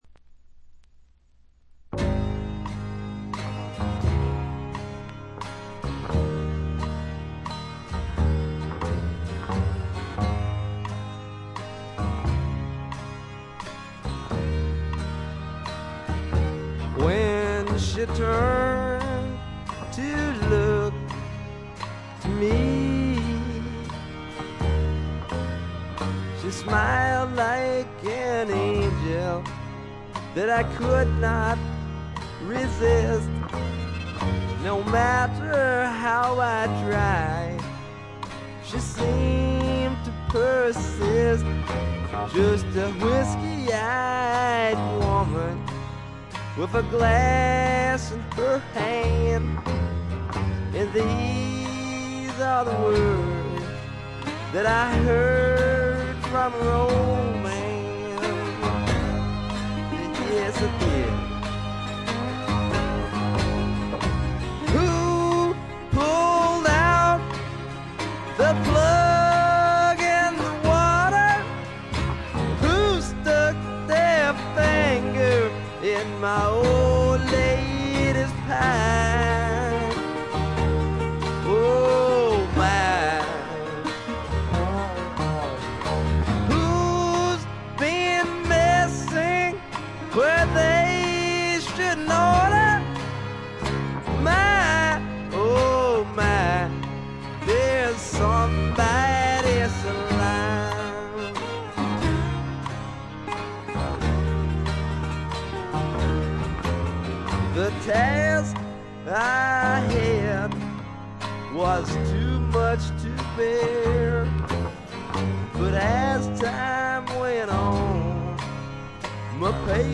微細なバックグラウンドノイズのみでほとんどノイズ感無し。
よりファンキーに、よりダーティーにきめていて文句無し！
試聴曲は現品からの取り込み音源です。